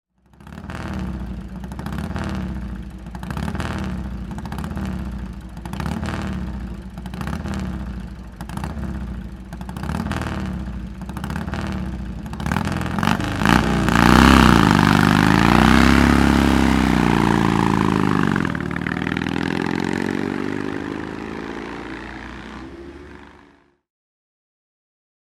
Morgan Super Aero (1928) - Start am Arosa ClassicCar Bergrennen 2013
Morgan_Super_Aero_1928.mp3